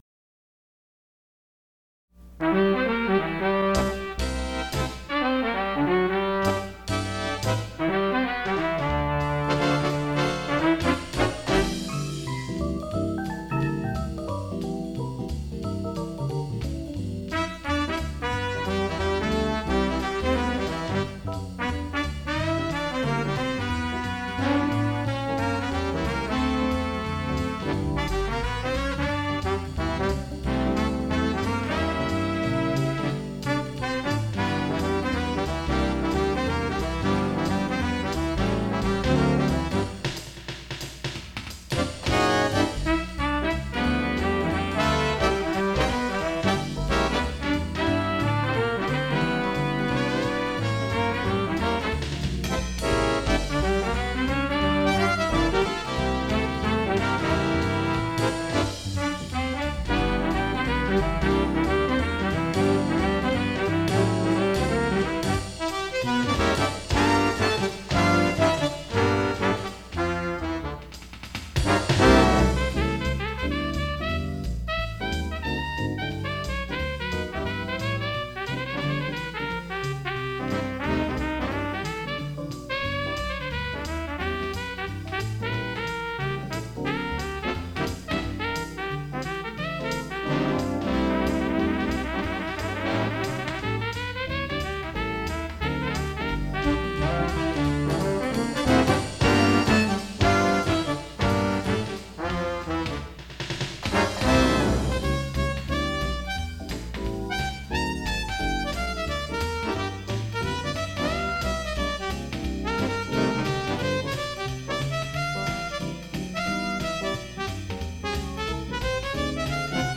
This page lists many of my original charts (compositions and arrangements) for big band.
In these cases, I generated MIDI-based recordings using Dorico and some nice sample libraries. I then used an audio editor to add solos (which I played from a keyboard) and piano comping as needed.